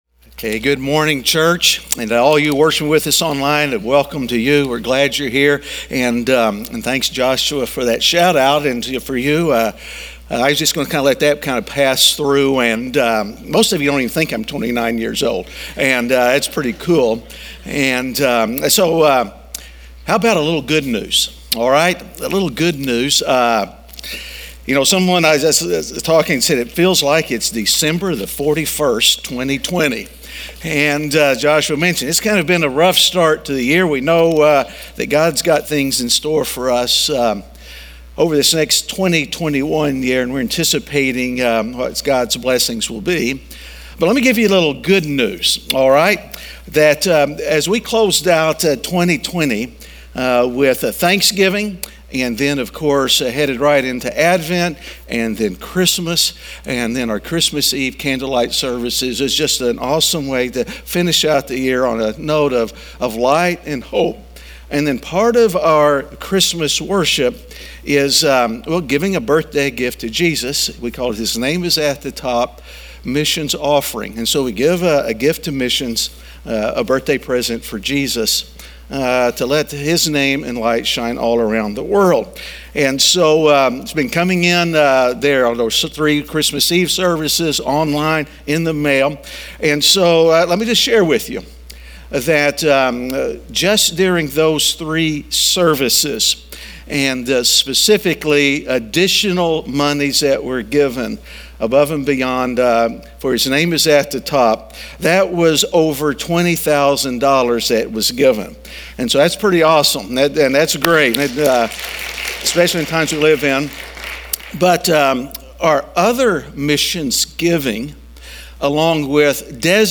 A message from the series "God Wrote a Book."